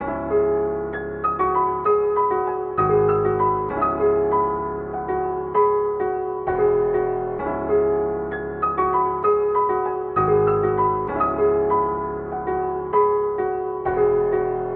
[AFSK] FreeBandz Loop BPM 130.wav